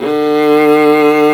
Index of /90_sSampleCDs/Roland L-CD702/VOL-1/STR_Viola Solo/STR_Vla2 _ marc